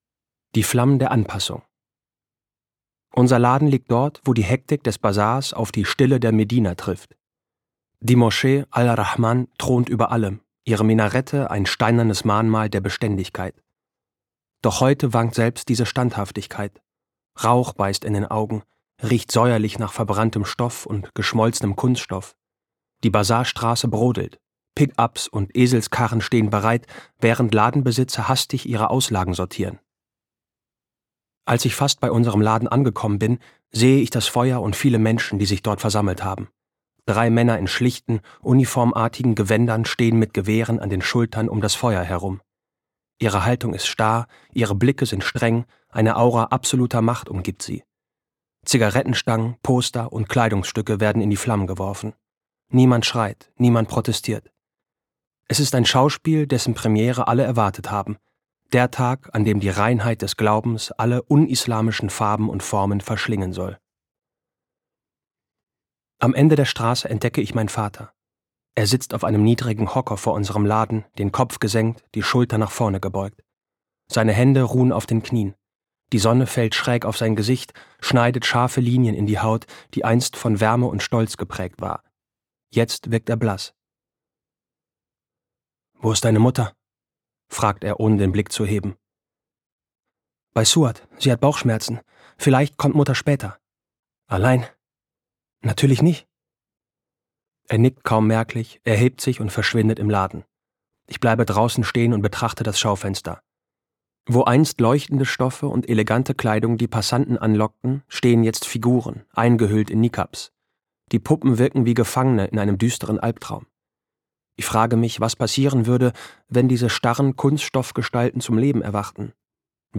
Details zum Hörbuch